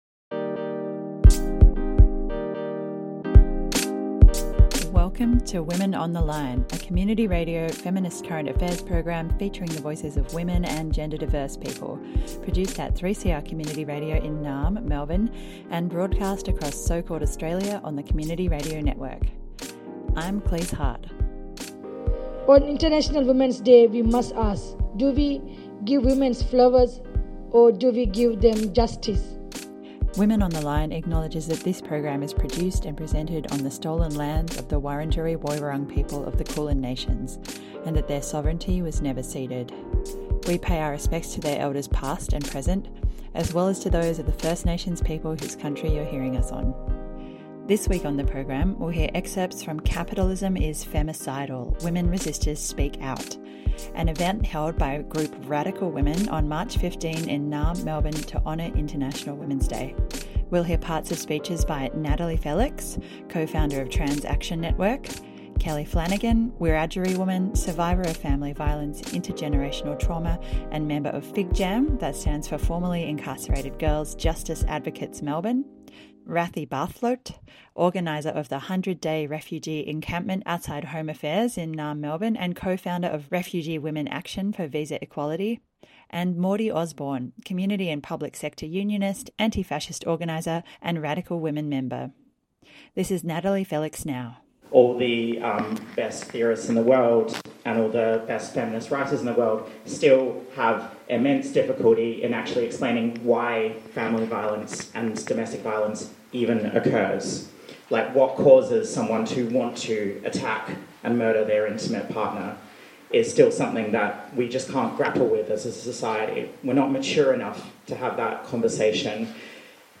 We’ll hear parts of speeches by: